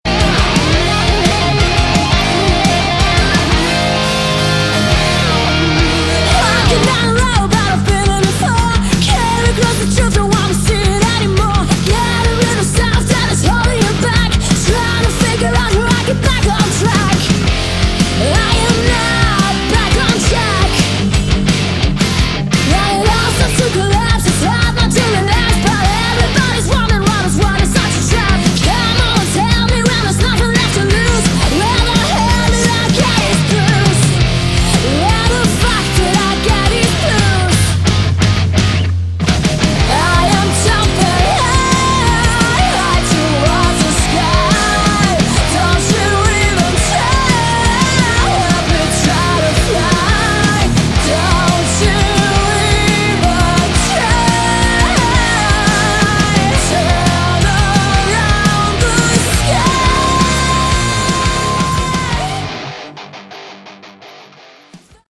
Category: Melodic Metal
vocals
guitars, keyboards
drums
guitars, backing vocals